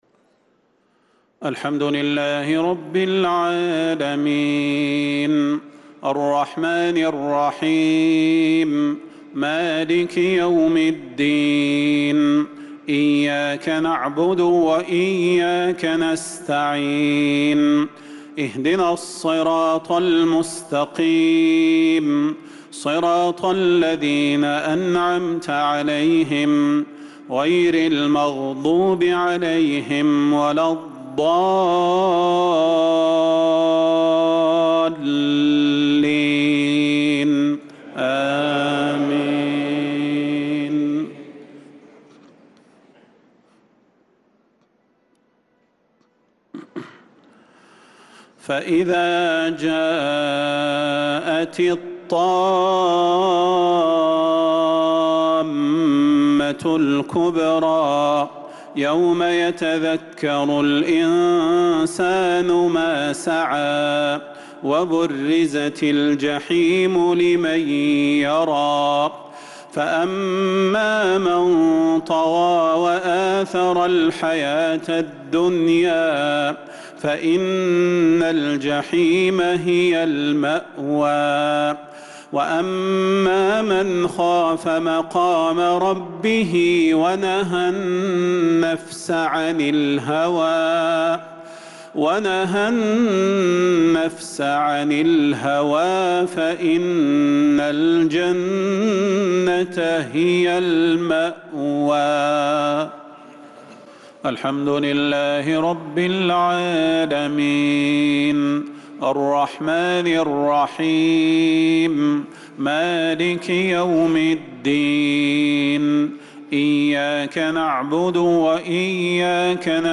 صلاة المغرب للقارئ صلاح البدير 14 شوال 1445 هـ
تِلَاوَات الْحَرَمَيْن .